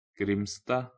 Grimstad (pronounced [ˈɡrɪ̀mstɑ]